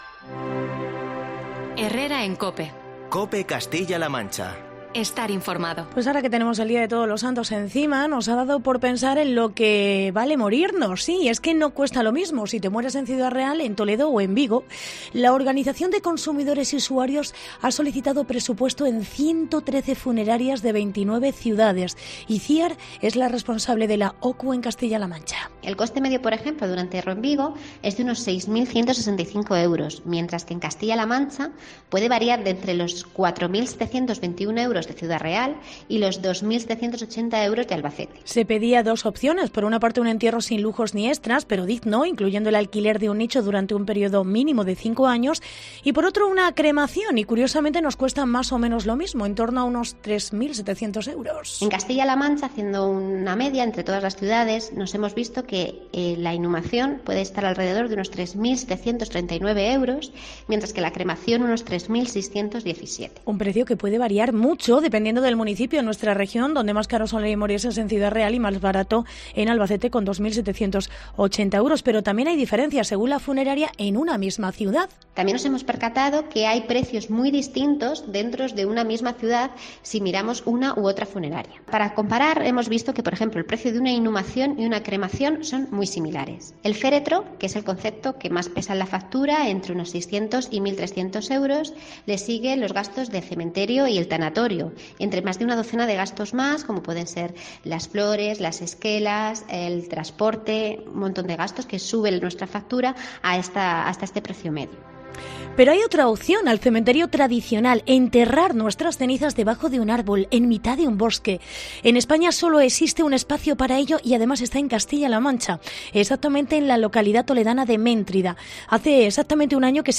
Reportaje Servicios funerarios